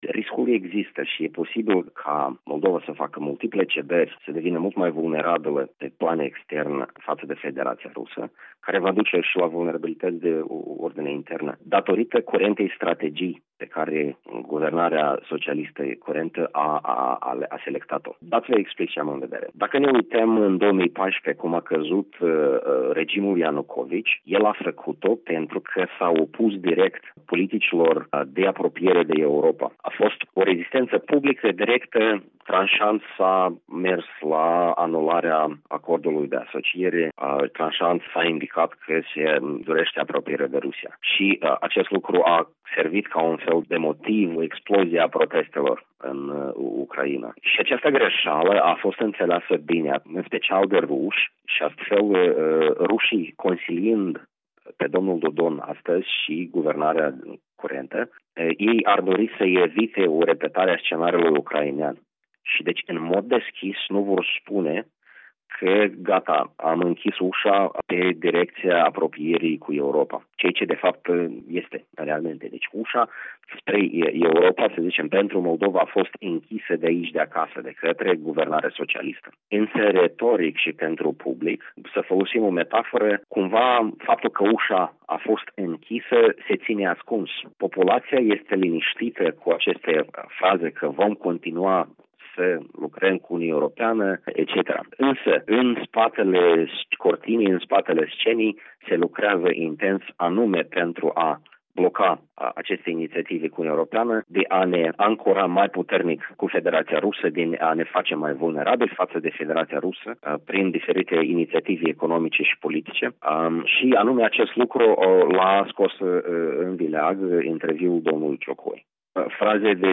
în dialog